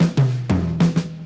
Jungle Fill.wav